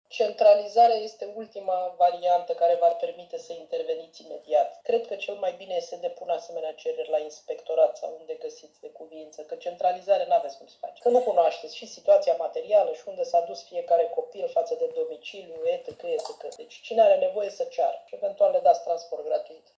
Desfășurată în format online, ședința de azi a Consiliului Local Constanța a avut pe ordinea de zi 3 proiecte de hotărâre.